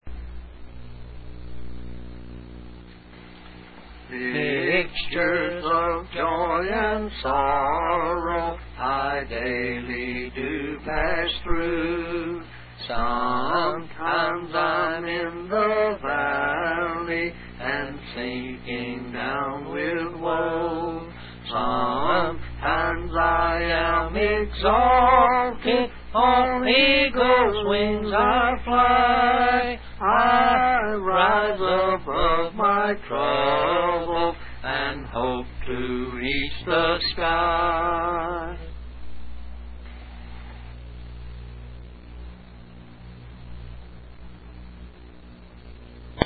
Mixtures of Joy and sorrows  A Georgia overdub
mixtures_duet_overdub.mp3